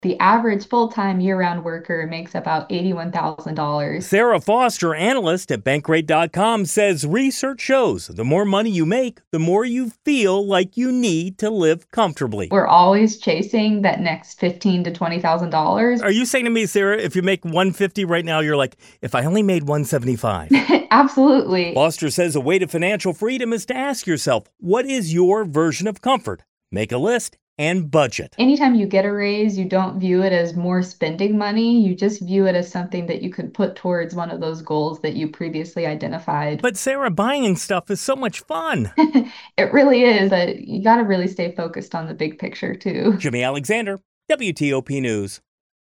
Live Radio